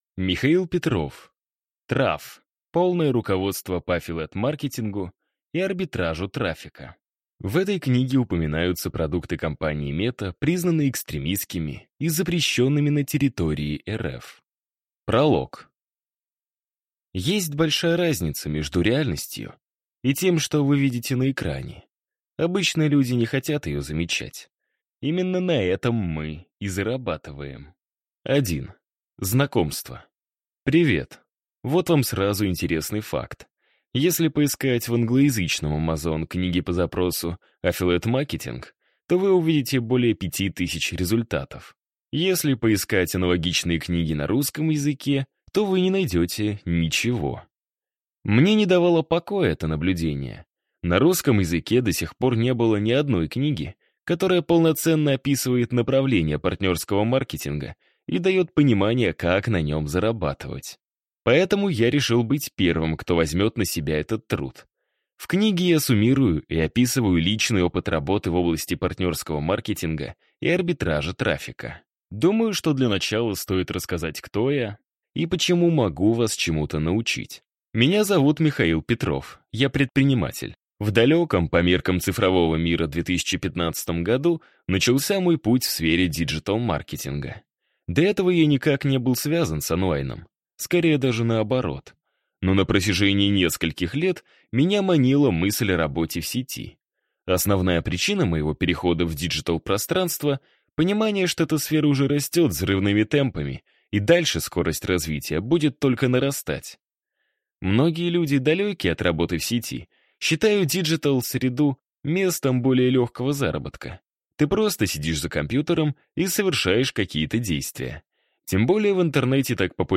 Аудиокнига Traff. Полное руководство по affiliate маркетингу и арбитражу трафика | Библиотека аудиокниг